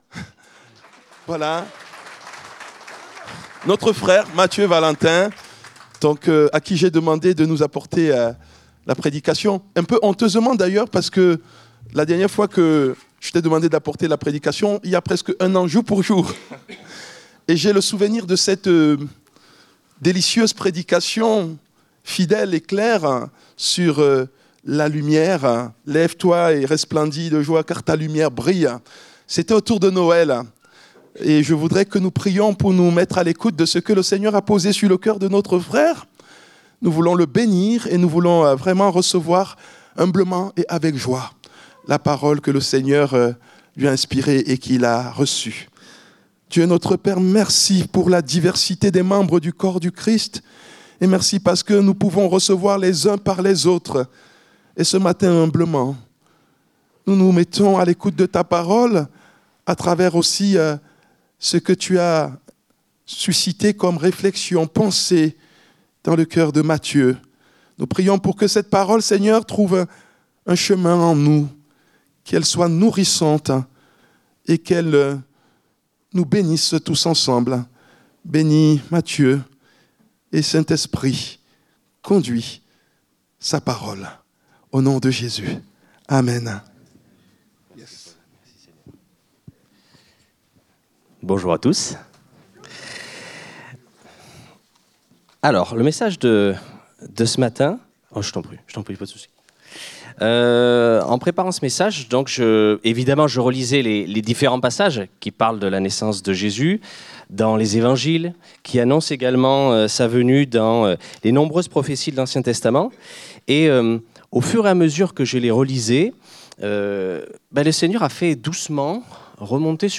Culte du dimanche 22 décembre 2024, prédication